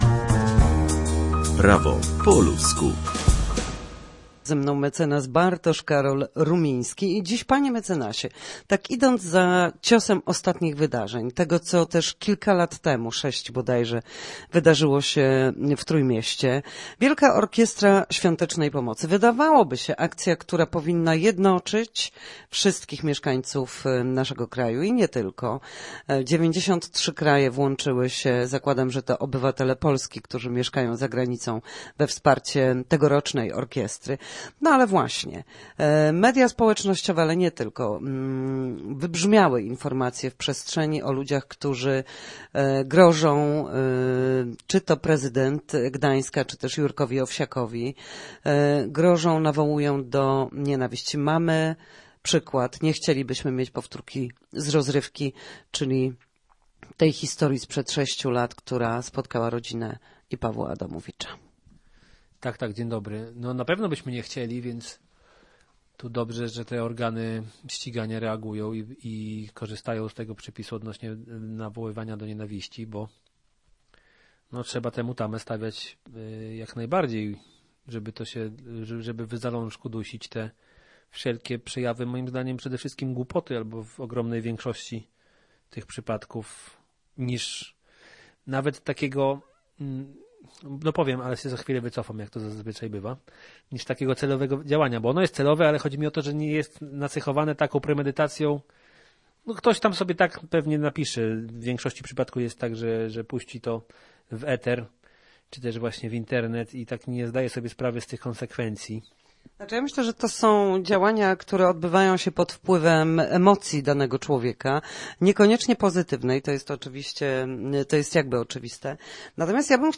W każdy wtorek o godzinie 13:40 na antenie Studia Słupsk przybliżamy państwu meandry prawa.